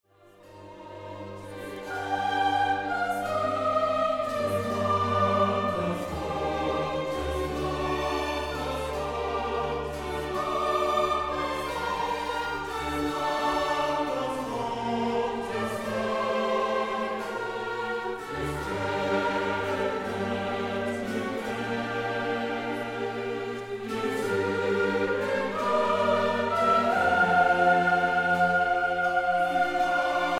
Festliches Konzert zu Ostern